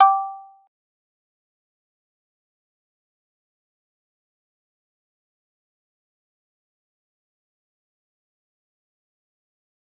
G_Kalimba-G5-pp.wav